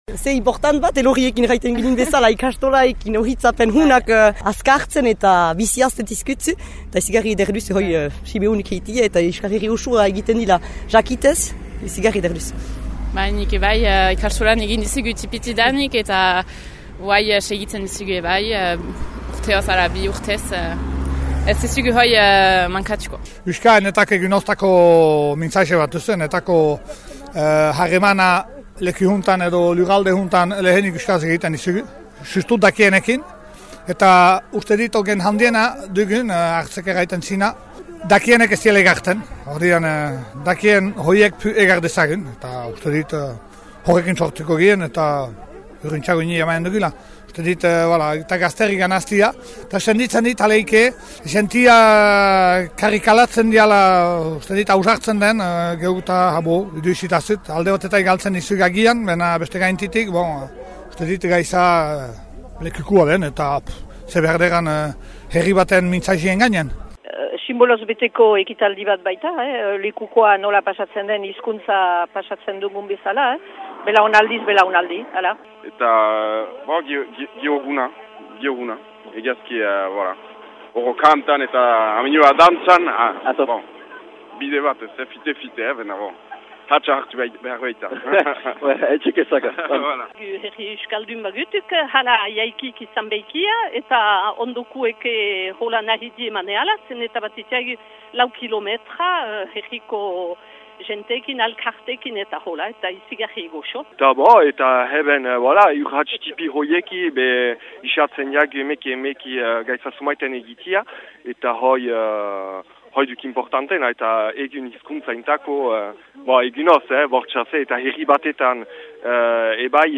korrika2017lekukotarzunak.mp3